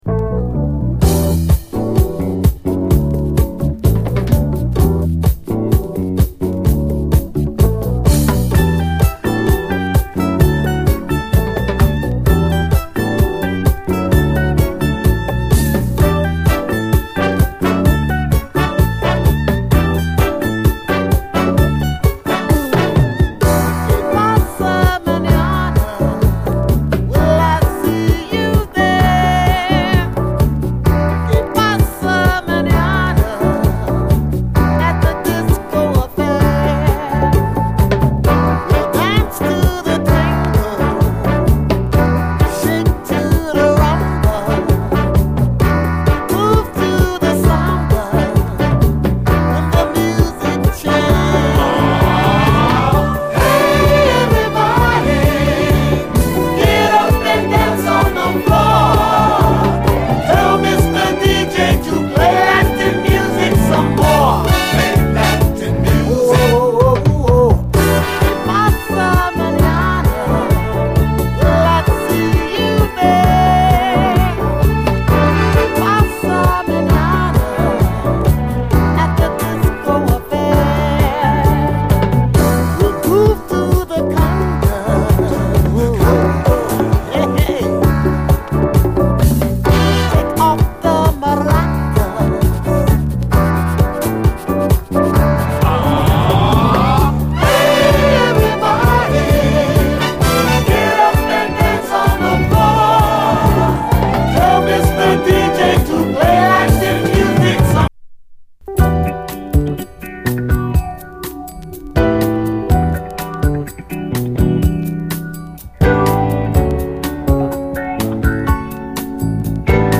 SOUL, 70's～ SOUL
ユニティー感と温もりが溢れる至福の一曲！